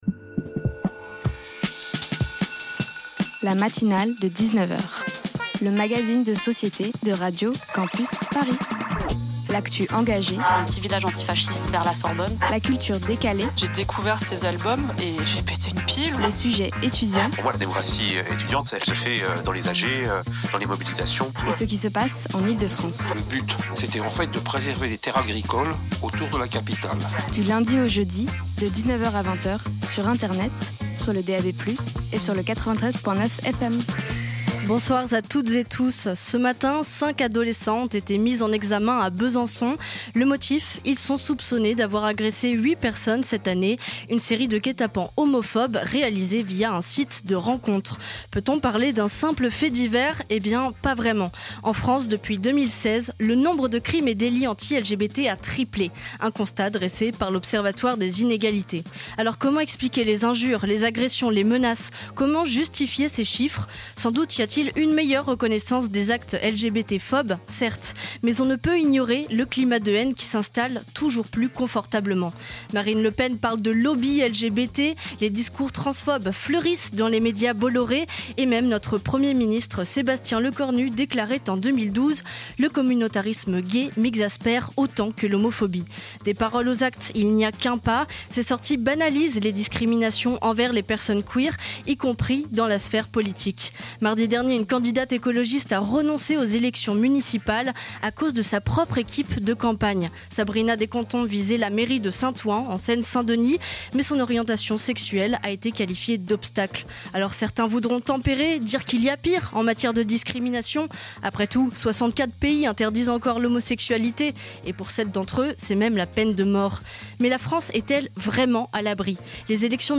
Art contre les violences sexistes & pop alternative avec Potager Partager Type Magazine Société Culture jeudi 27 novembre 2025 Lire Pause Télécharger Ce soir